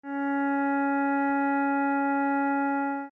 Db4.mp3